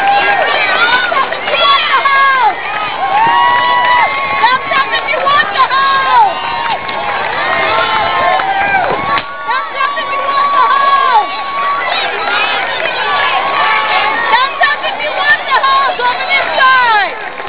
mile 20 on a hot boston marathon
"thumbs up if you want the hose!" which sounded kind of familiar, then i realized it was kind of similar to silence of the lamb's "it puts the lotion in the basket or else it gets the hose again." i shuddered. besides, thumbs up always means "you're cool" and if you're cool, you don't need to get sprayed with water. they should've thought of using another gesture.
get_the_hose.wav